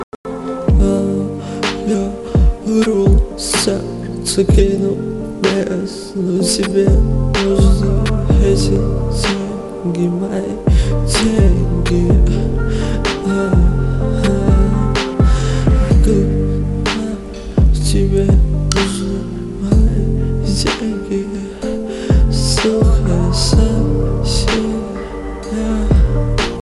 • Качество: 192, Stereo
мужской вокал
русский рэп
спокойные
Спокойный трек от неизвестного исполнителя.